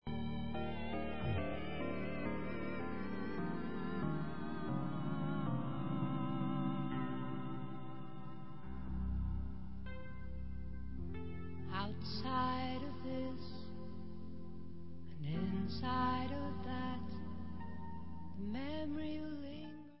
Pop/Symphonic